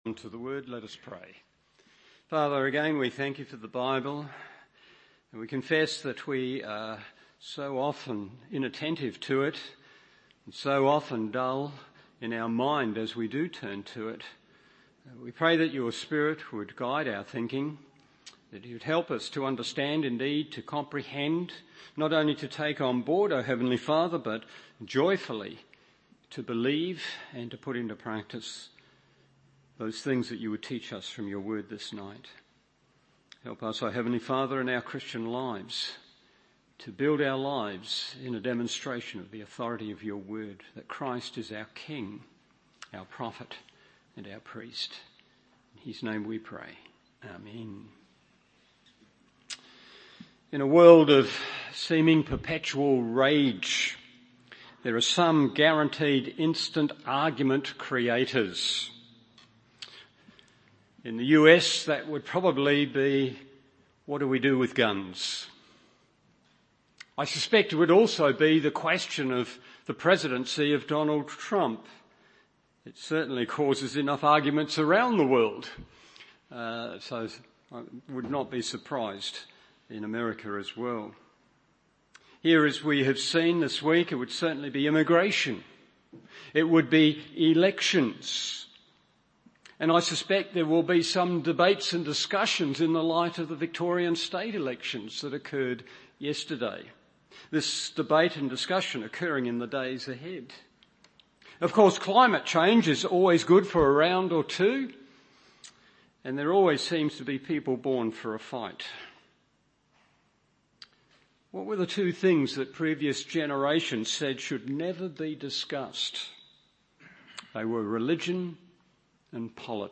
Evening Service Acts 21:27-40 1. Accusations cause Riot 2. Arrest is Made 3. Accused’s Surprise